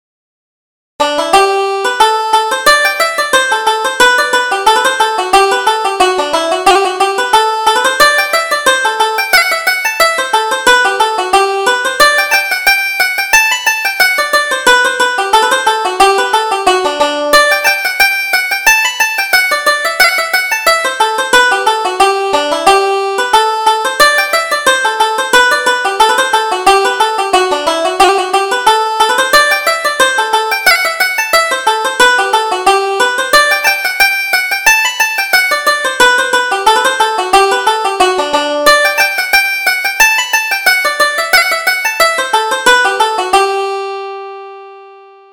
Reel: The Shaskeen Reel